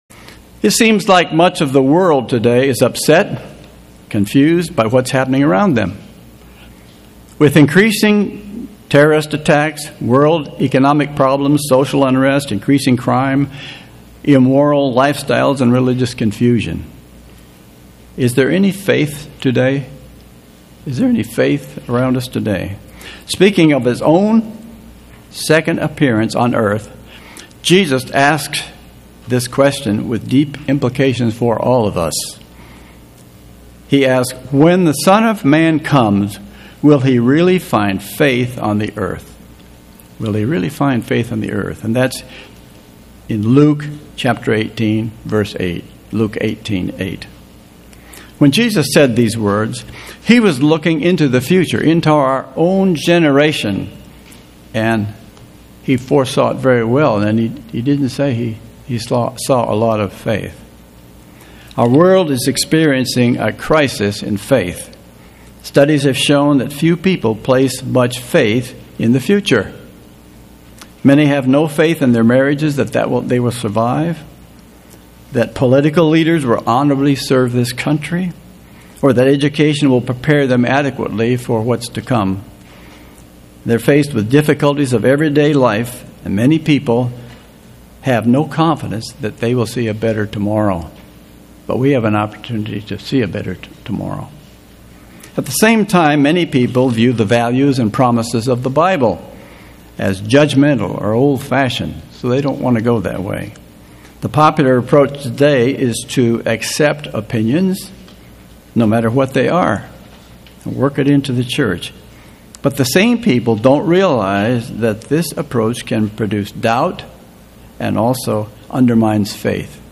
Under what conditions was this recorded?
[Note - This sermon was given in the Atlanta, GA and Buford, GA congregations. The audio message is the one given in Atlanta on 9-10-16.]